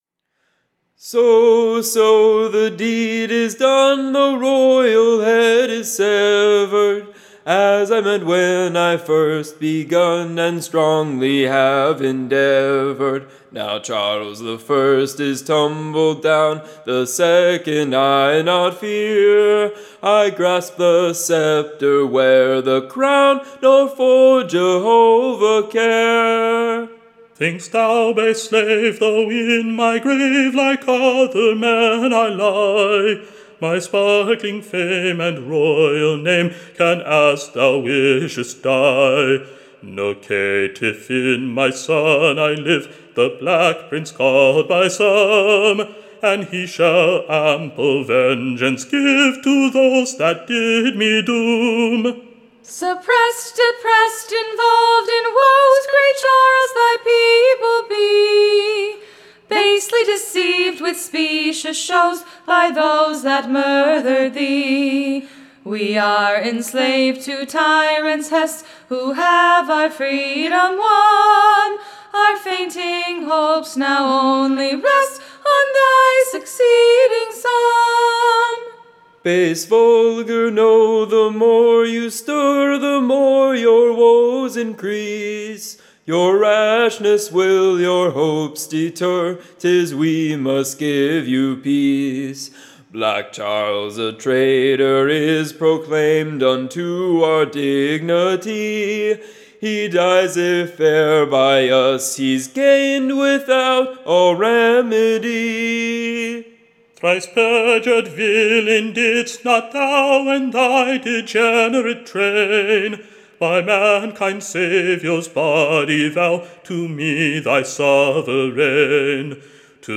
Recording Information Ballad Title A COFFIN FOR KING CHARLES: / A CROWNE FOR CROMWELL: / A PIT FOR THE PEOPLE. Tune Imprint You may sing this to the Tune of faine I would.